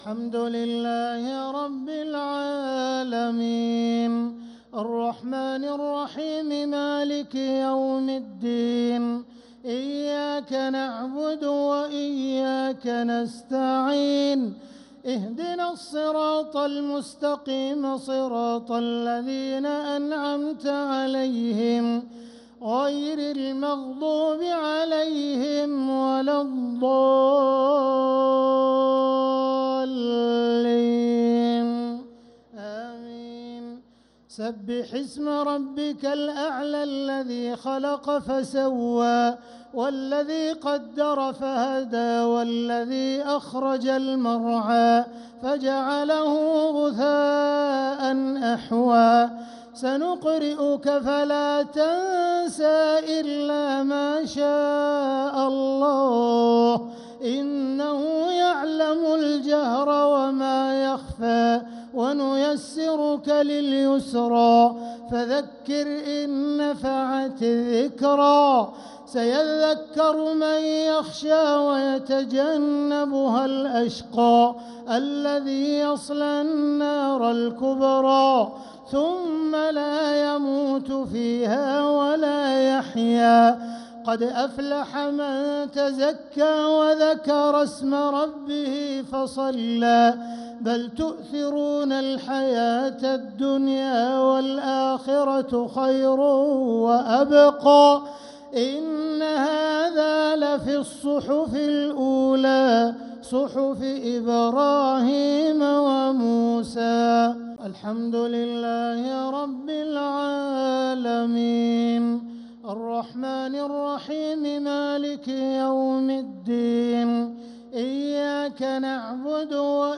الشفع و الوتر ليلة 13 رمضان 1446هـ | Witr 13th night Ramadan 1446H > تراويح الحرم المكي عام 1446 🕋 > التراويح - تلاوات الحرمين